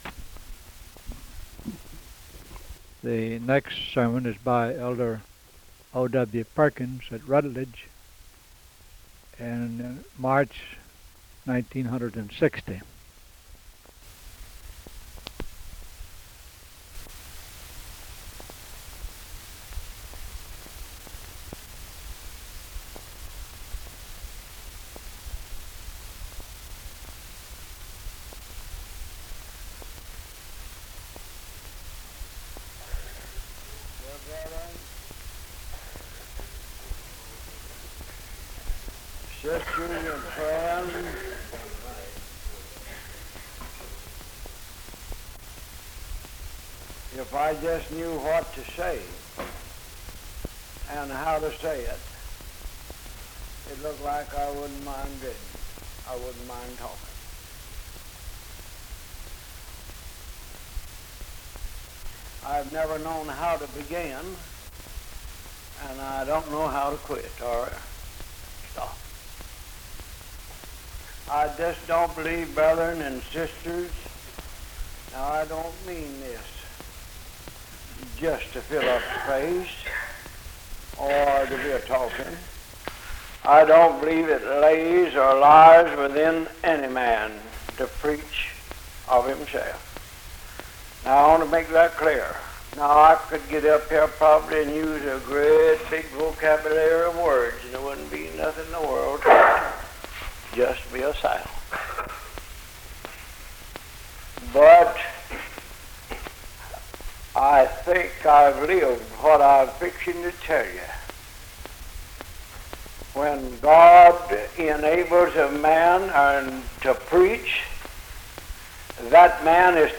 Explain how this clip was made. Sermon from Rutledge Church